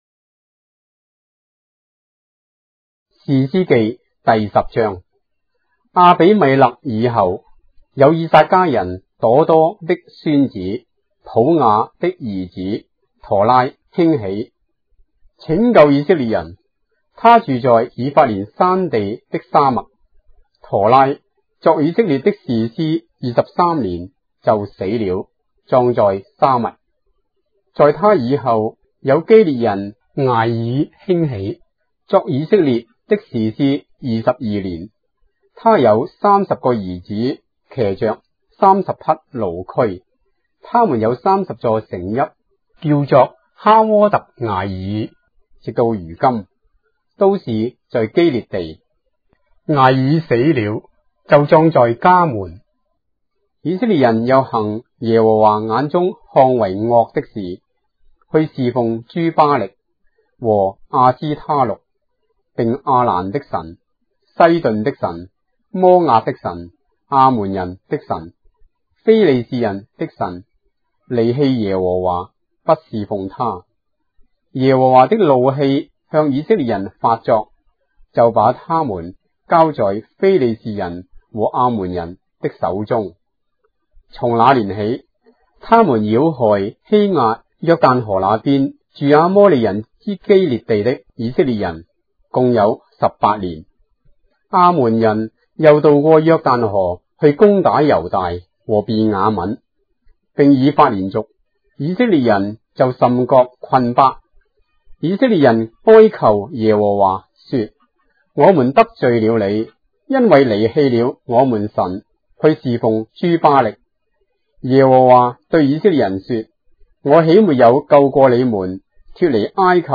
章的聖經在中國的語言，音頻旁白- Judges, chapter 10 of the Holy Bible in Traditional Chinese